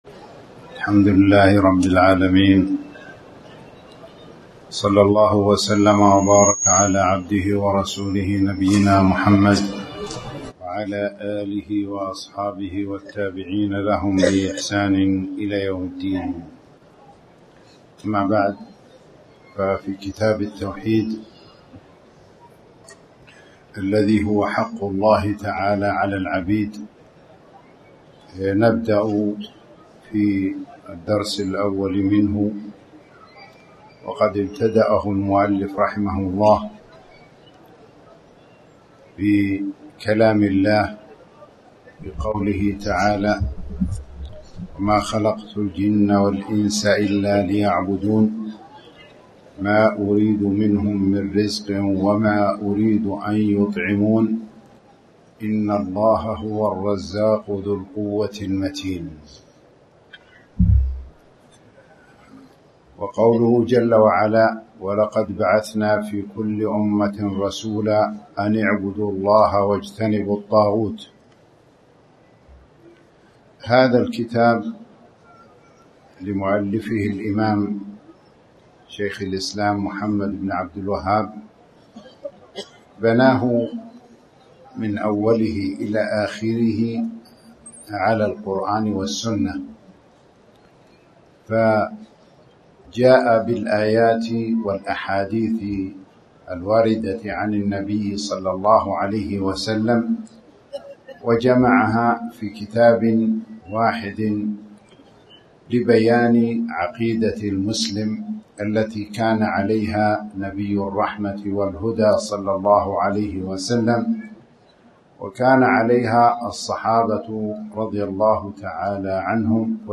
تاريخ النشر ٤ محرم ١٤٣٩ هـ المكان: المسجد الحرام الشيخ